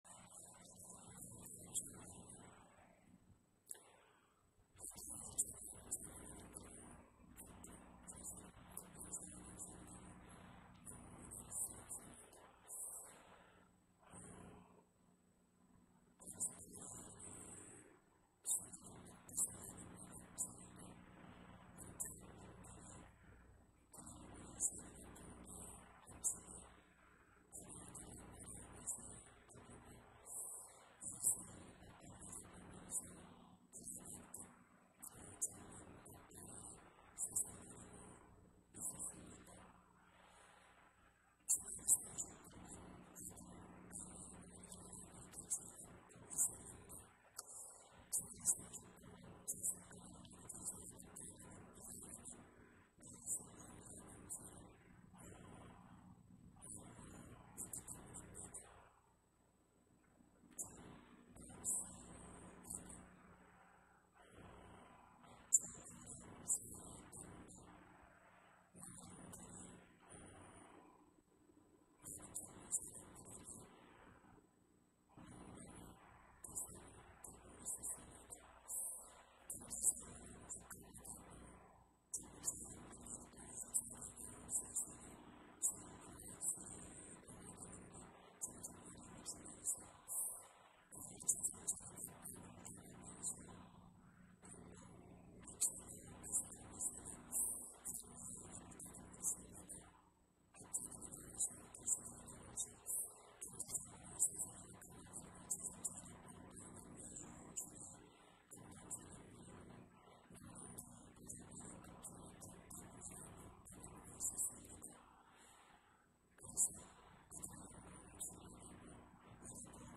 2021년 5월 9일 주일 4부 예배